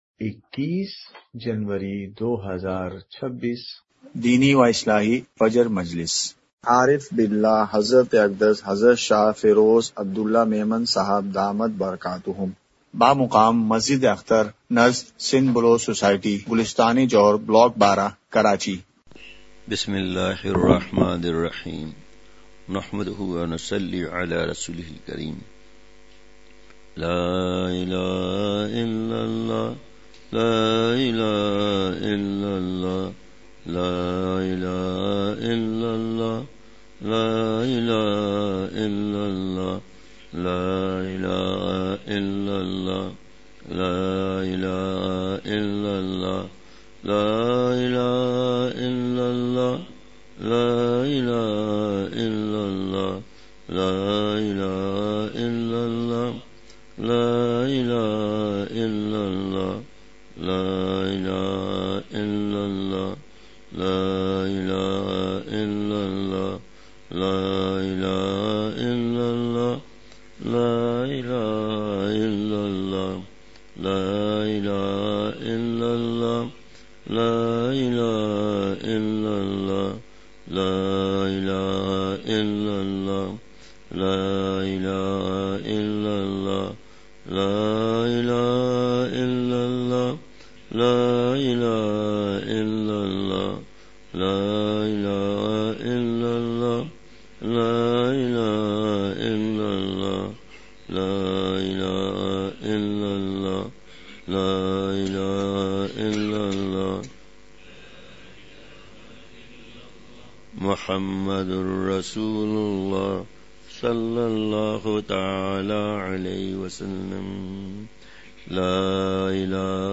فجر مجلس ۲۱ جنوری ۲۶ء:حرمین شریفین میں حفاظت نظر !
*مقام:مسجد اختر نزد سندھ بلوچ سوسائٹی گلستانِ جوہر کراچی*